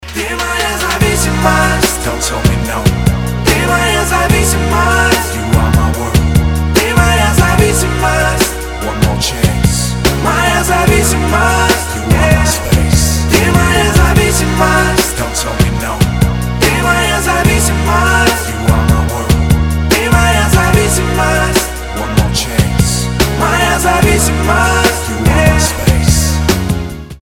• Качество: 320, Stereo
поп
мужской вокал
спокойные
романтичные